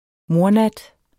Udtale [ ˈmoɐ̯- ]